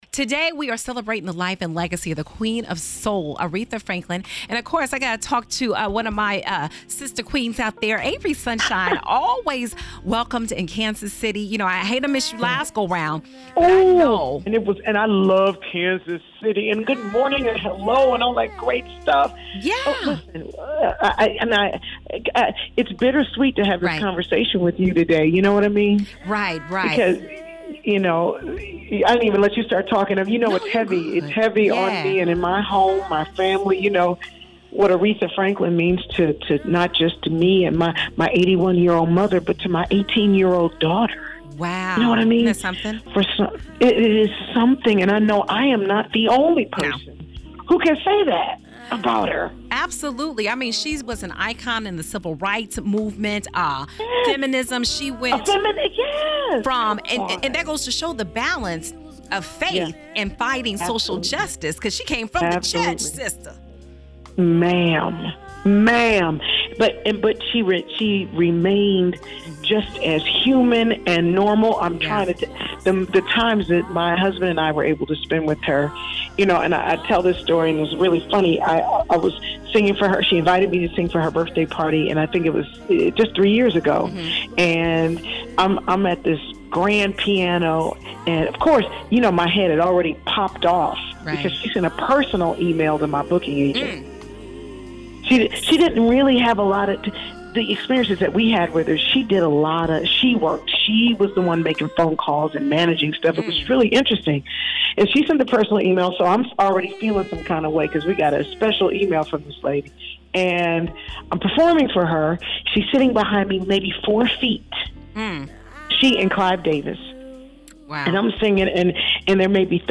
Albeit her influence is unrivaled, I had the chance to speak with singer Avery Sunshine on her memories of Aretha – and the biggest factor of the Queen’s life she wants us to remember.